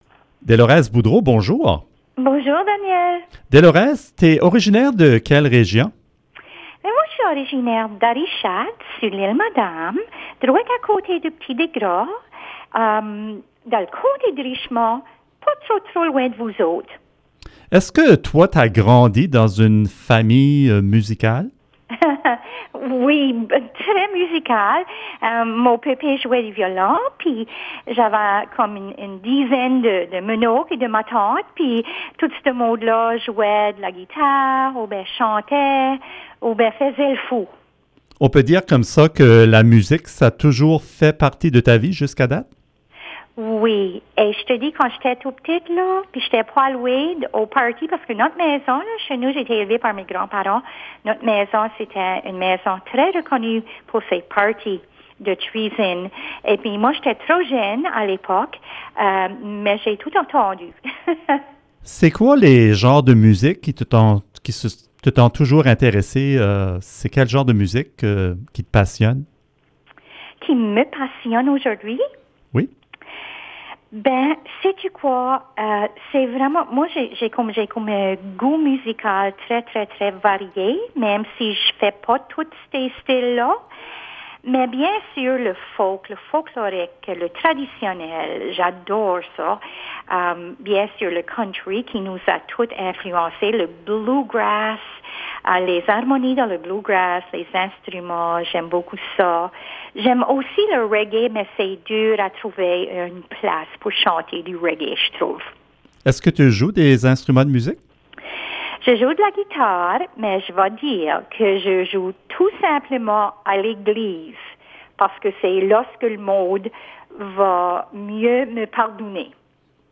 Pour notre reportage d'aujourd'hui, nous avons choisi de vous présenter un portrait d'une artiste acadienne de la Nouvelle-Écosse.